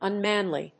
音節un・man・ly 発音記号・読み方
/`ʌnmˈænli(米国英語)/